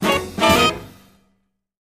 Music Effect; Small Jazz Band Hits.